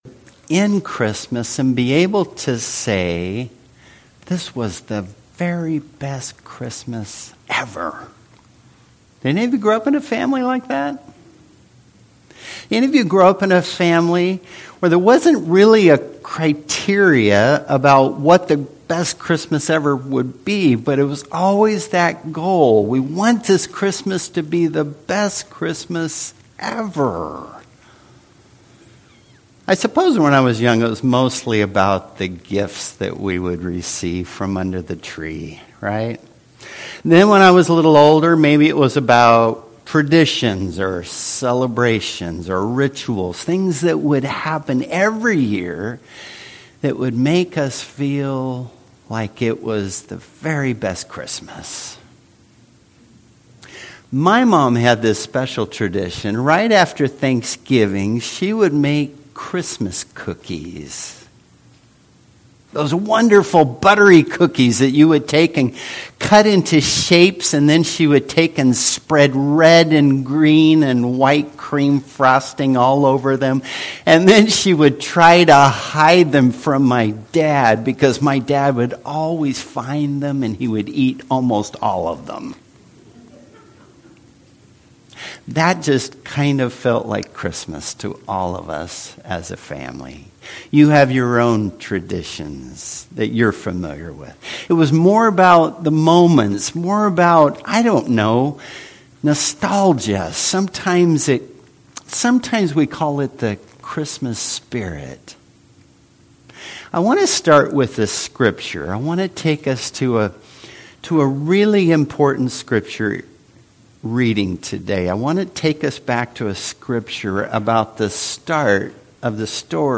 Passage: 2 Corinthians 9:10-15 Service Type: Worship Service